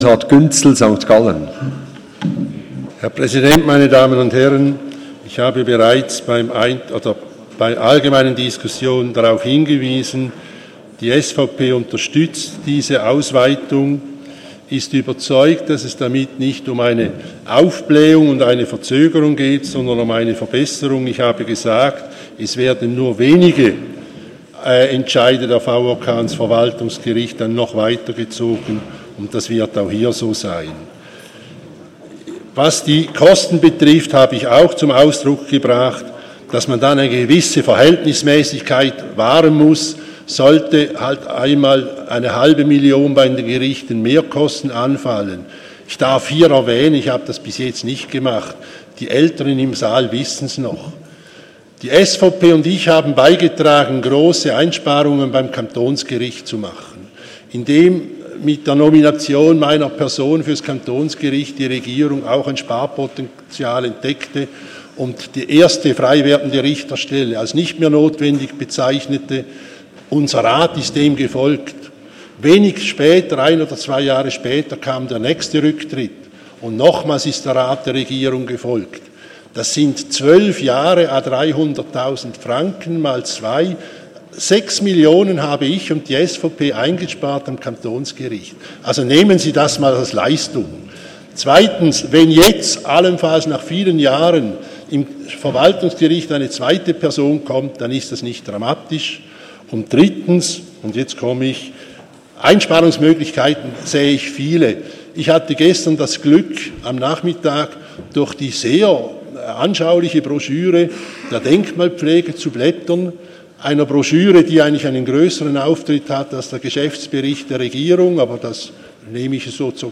20.9.2016Wortmeldung
Session des Kantonsrates vom 19. und 20. September 2016